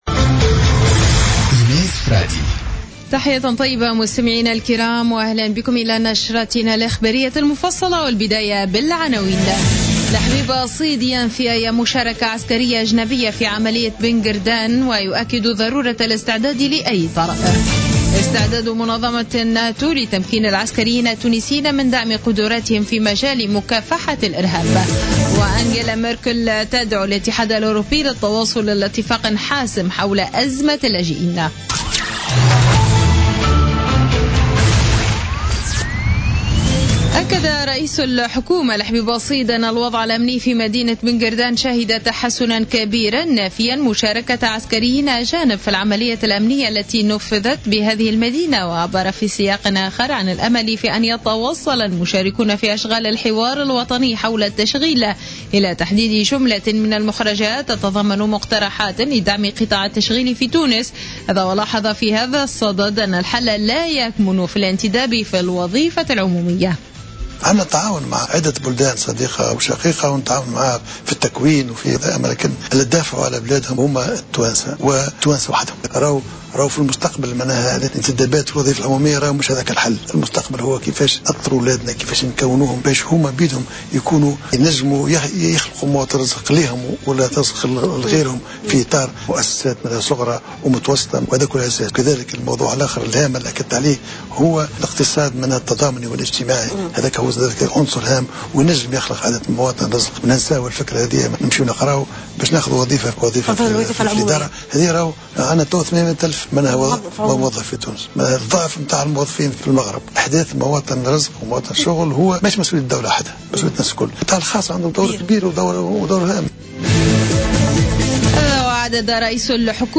نشرة أخبار منتصف الليل ليوم الخميس 17 مارس 2016